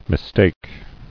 [mis·take]